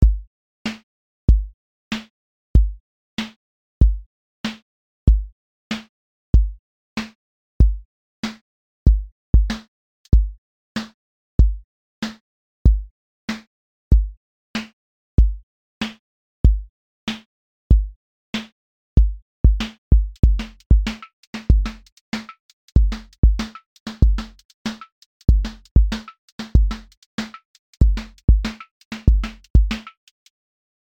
QA Listening Test boom-bap Template: boom_bap_drums_a
boom-bap pocket with restrained texture release for canonical handoff verification
• voice_kick_808
• voice_snare_boom_bap
• voice_hat_rimshot
• tone_warm_body
• motion_drift_slow